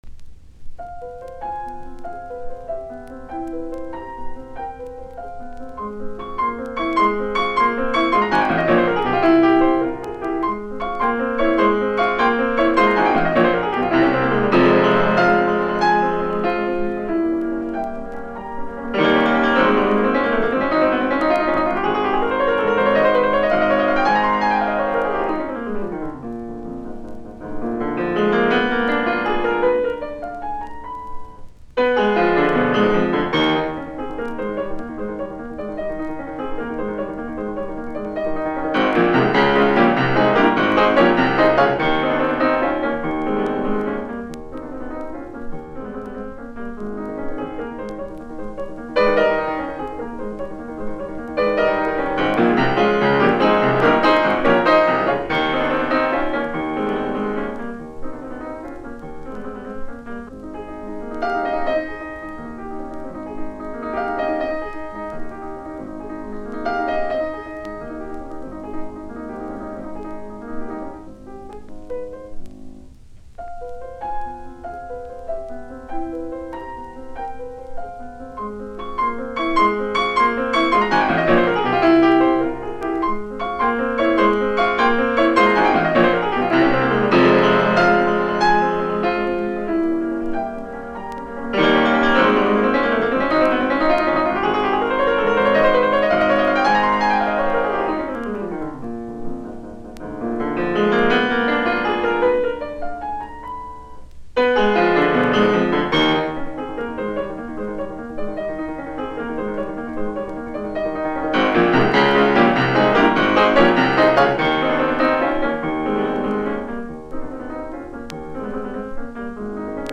musiikkiäänite
Soitinnus: Piano.